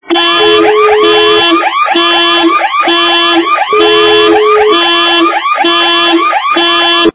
При прослушивании на СМС - Автосигнализация с горном качество понижено и присутствуют гудки.
Звук на СМС - Автосигнализация с горном